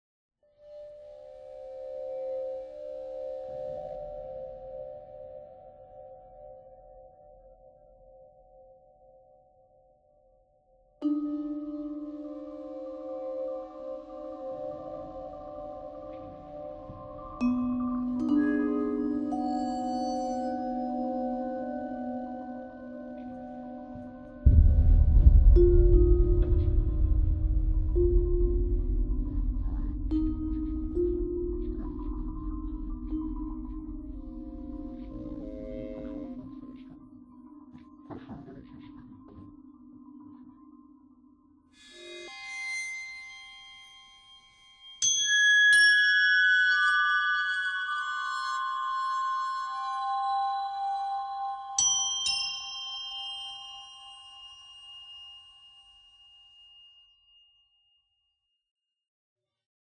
piano
trumpet